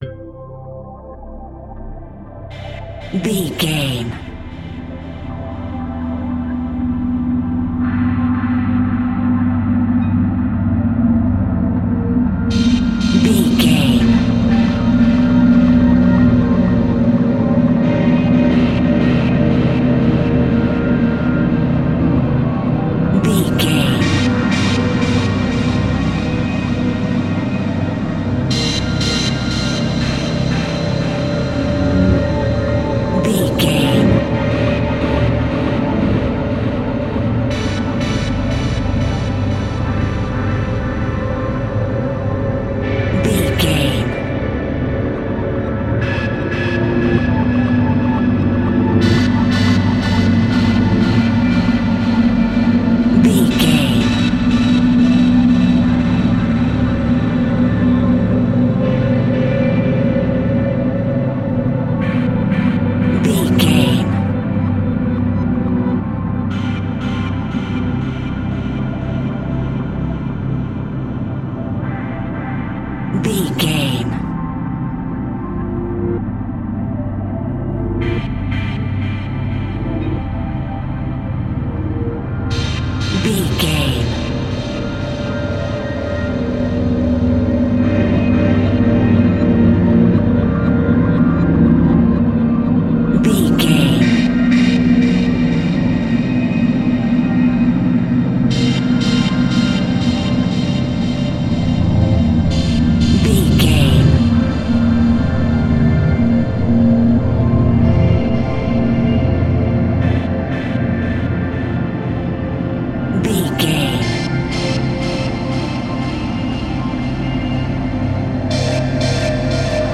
Reverse Horror Music.
In-crescendo
Atonal
Slow
scary
tension
ominous
dark
suspense
haunting
eerie
synthesiser
creepy
Synth Pads
atmospheres